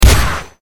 shotgun.mp3